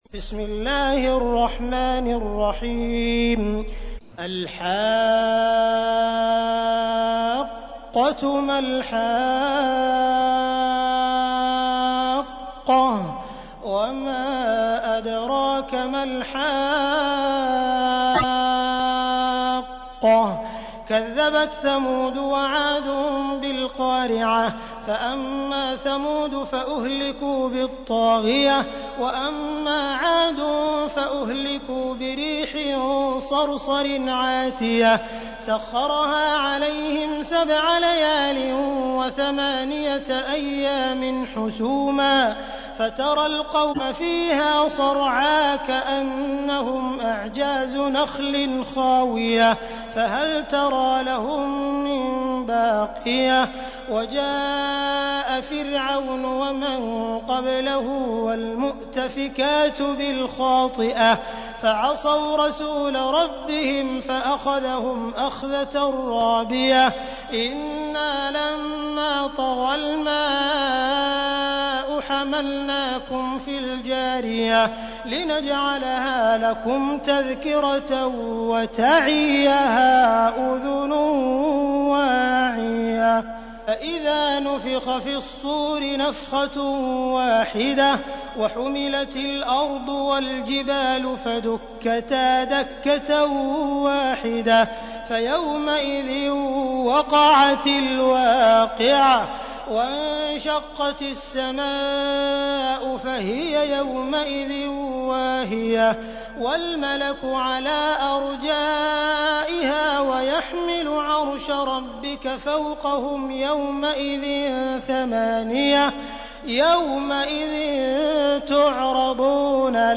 تحميل : 69. سورة الحاقة / القارئ عبد الرحمن السديس / القرآن الكريم / موقع يا حسين